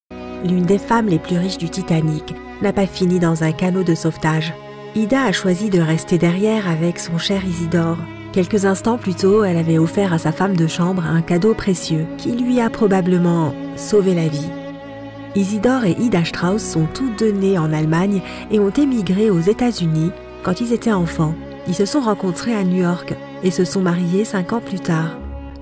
Female
Most importantly, I handle all the technical mastering work (-23dB to -18dB| kbps) and formatting in my home studio.
Audiobooks